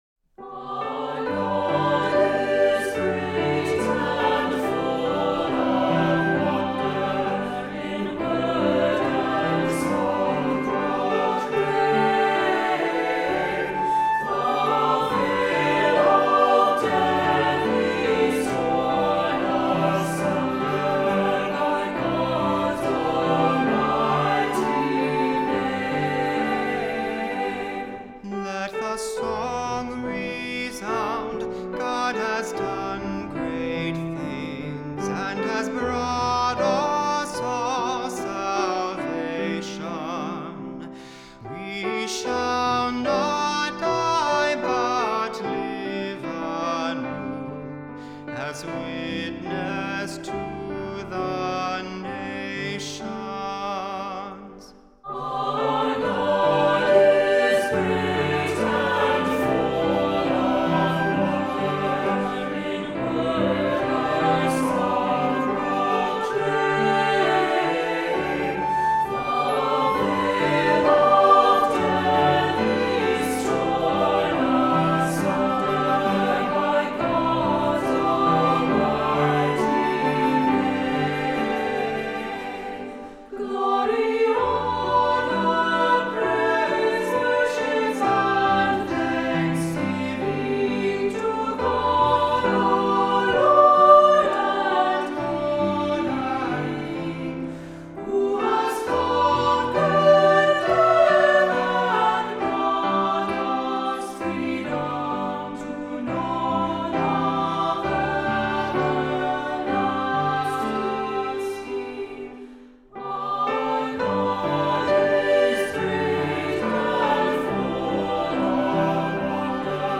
Voicing: SATB; Descant; Assembly